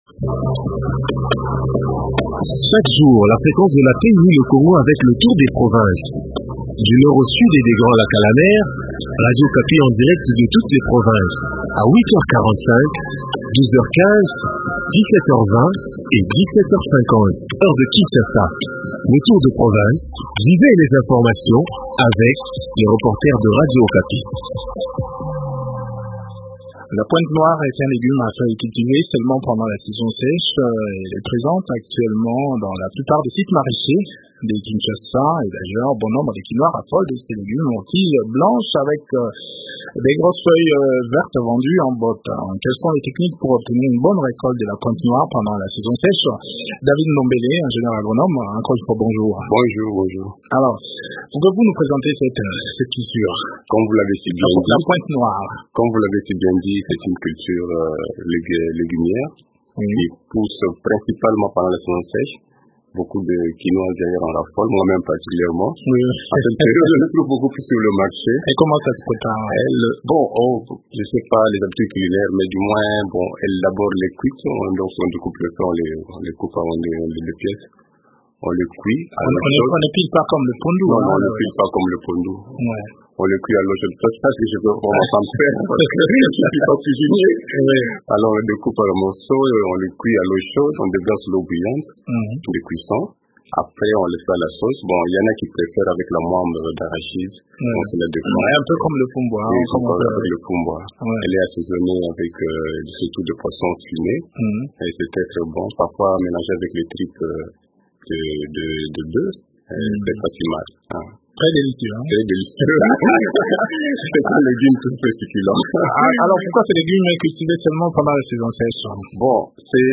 ingénieur agronome est l’invité du magazine Okapi service